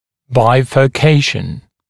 [ˌbaɪfɜː’keɪʃn][ˌбайфё:’кейшн]бифуркация, разделение на две ветви